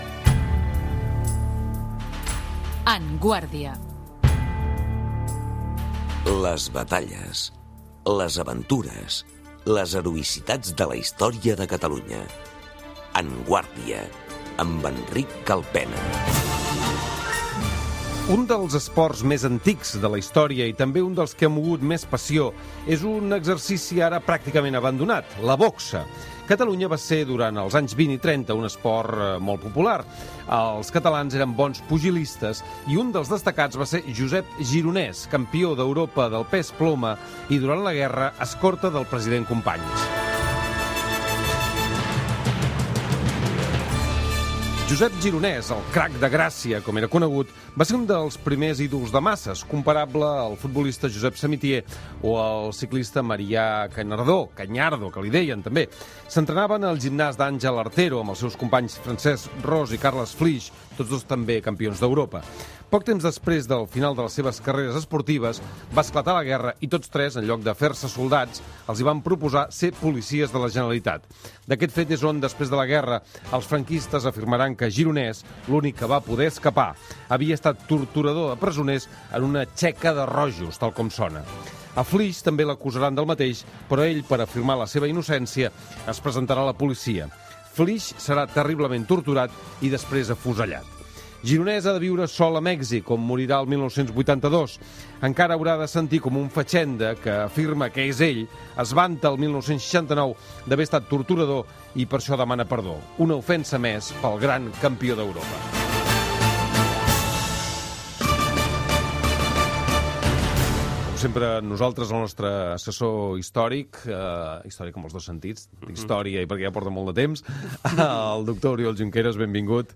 Descripció Careta del programa, presentació i espai dedicat al boxejador català Josep Gironès.